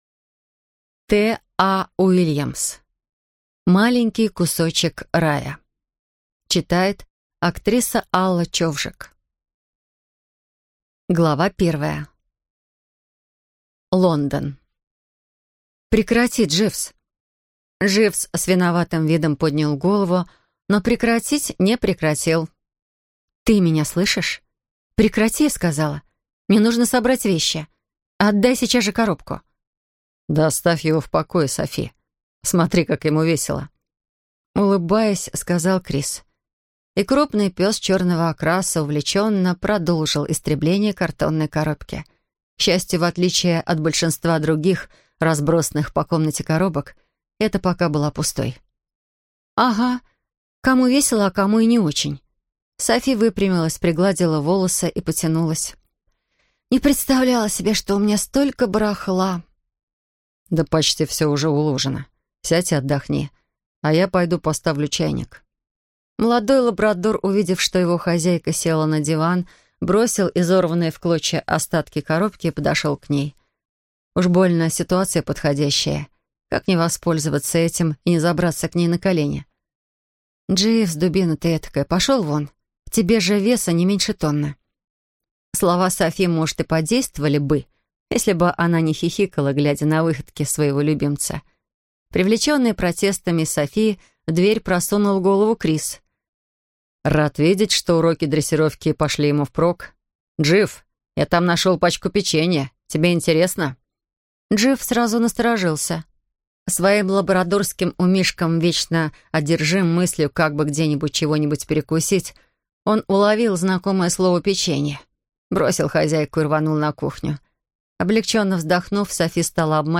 Аудиокнига Маленький кусочек рая | Библиотека аудиокниг